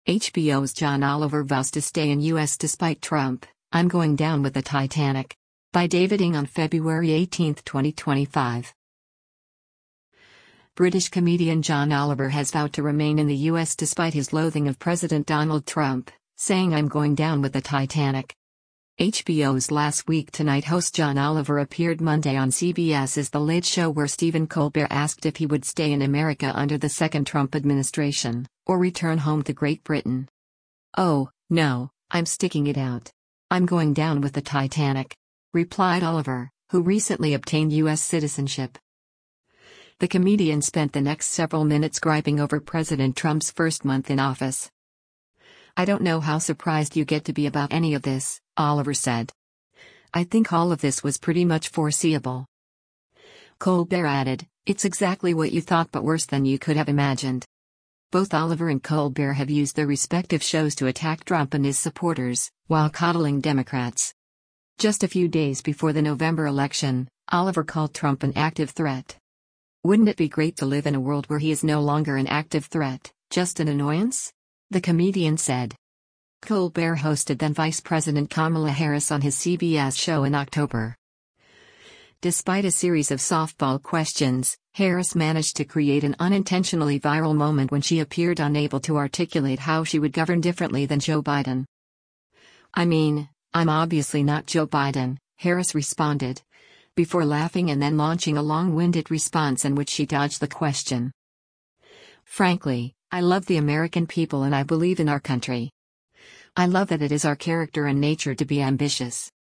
HBO’s Last Week Tonight host John Oliver appeared Monday on CBS’s The Late Show where Stephen Colbert asked if he would stay in America under the second Trump administration, or return home to Great Britain.